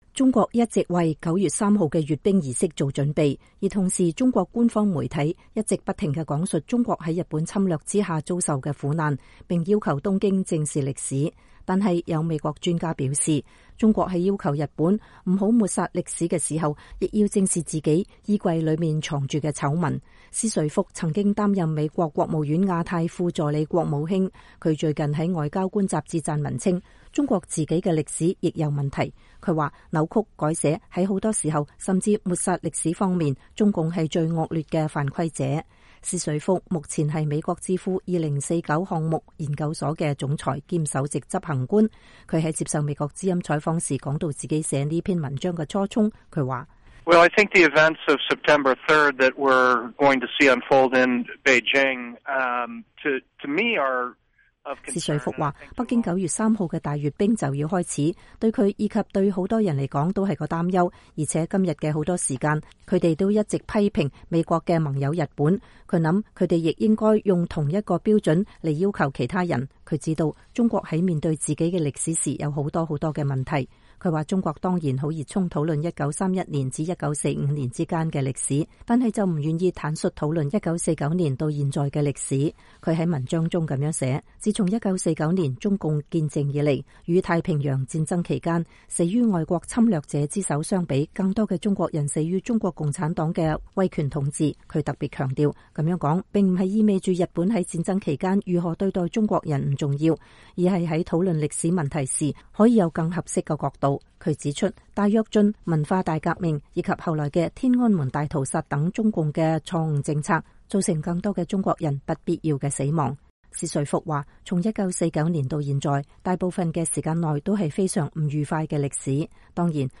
施瑞福目前是美國智庫2049項目研究所的總裁兼首席執行官，他在接受美國之音採訪時談到自己寫這篇文章的初衷。